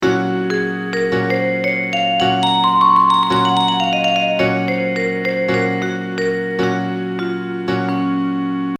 I quickly realized you could get more gradual patterns with larger numbers: